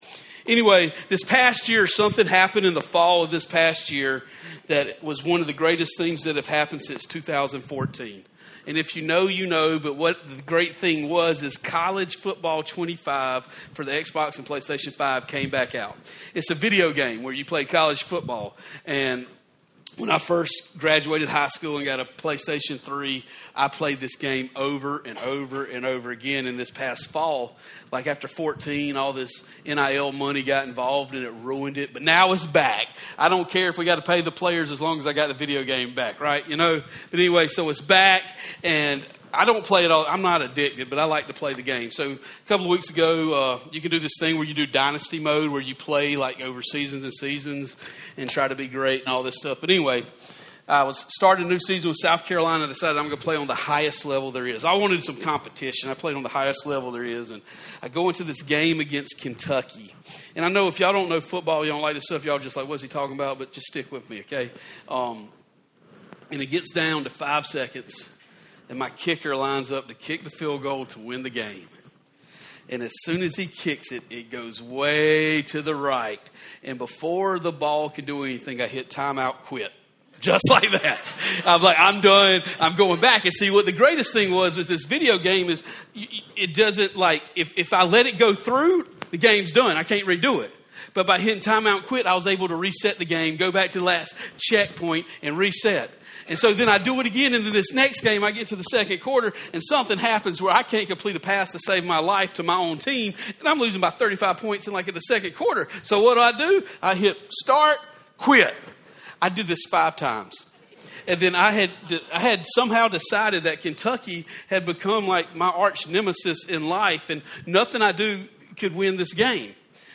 From Campus: "RADIUS Saluda "